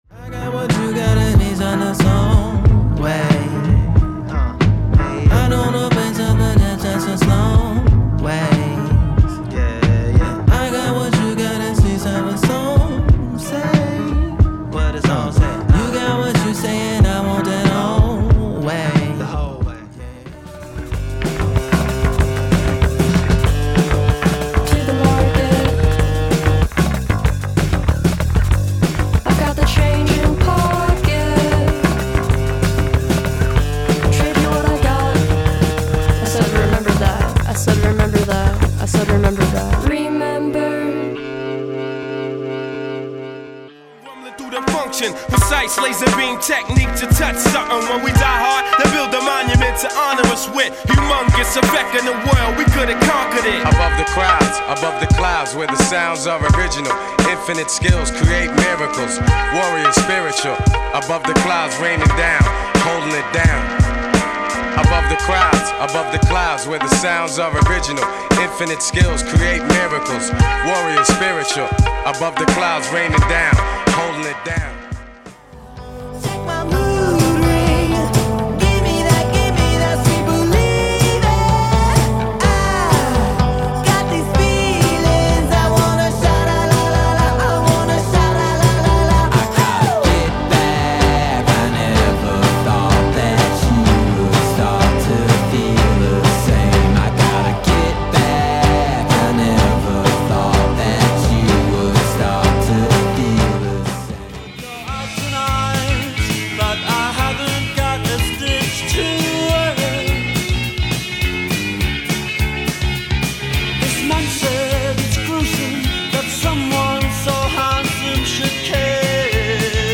Description: Extreme mix of Rock, Alternative, and Hip-Hop.